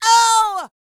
D-YELL 2601.wav